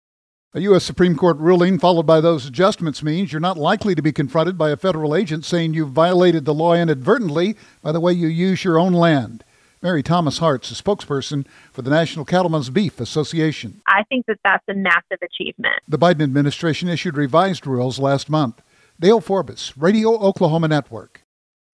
commentary from NCBA's